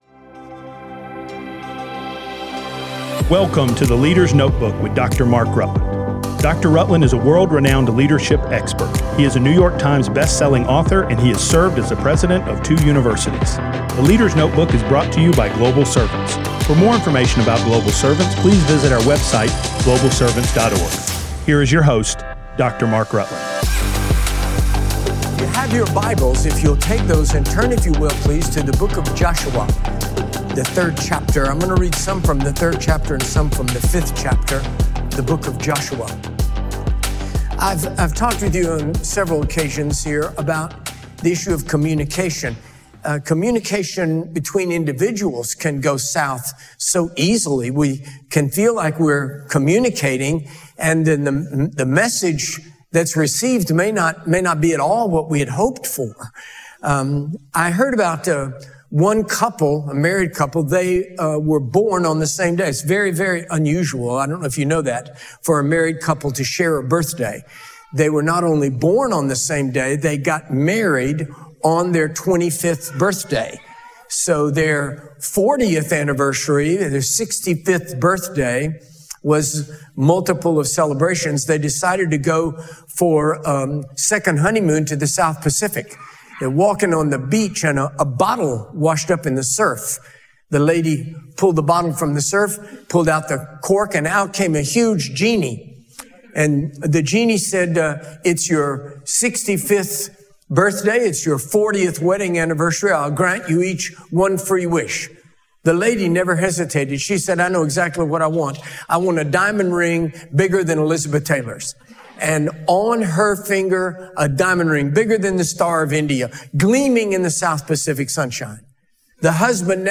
This message is about the moment of God, when His voice cuts through confusion and calls us to clean out what hinders us, expect His supernatural power, and move when the Holy Spirit leads.